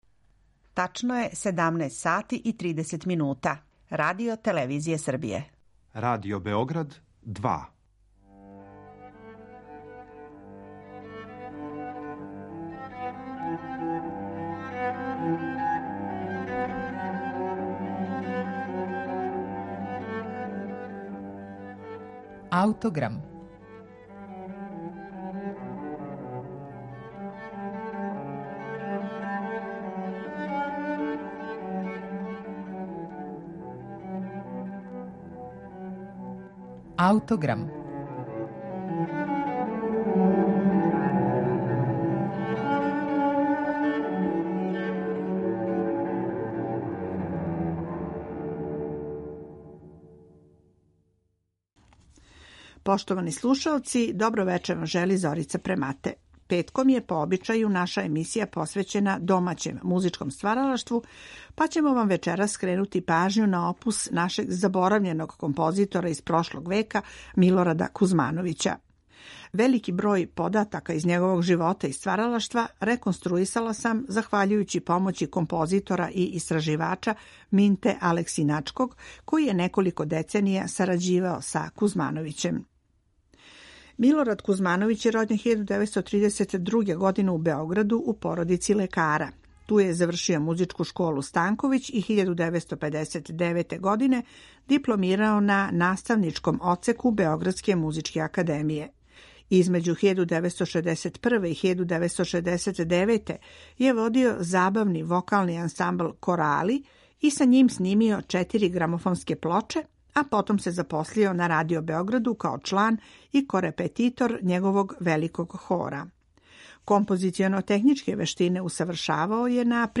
Вече ће започети његов Концерт за гудаче који на нашем снимку свира Београдски гудачки оркестар „Душан Сковран"